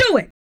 03 RSS-VOX.wav